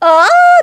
Worms speechbanks
ow2.wav